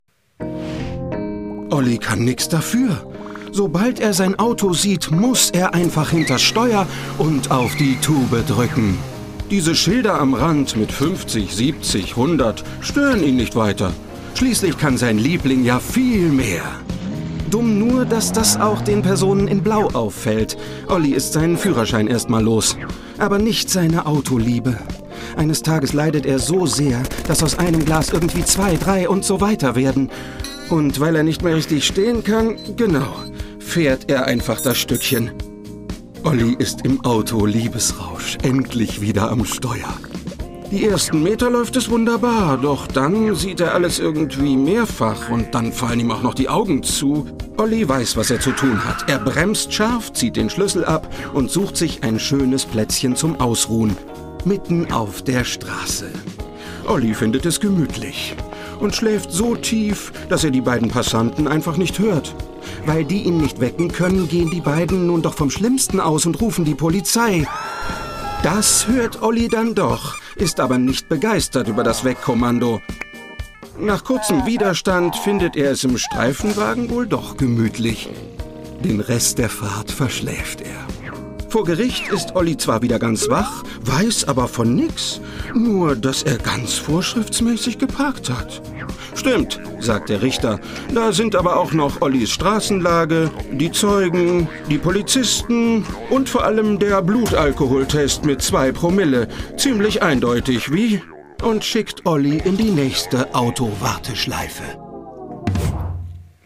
Mittel plus (35-65)
Comedy, Doku, Comment (Kommentar)